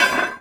gibmetal3.wav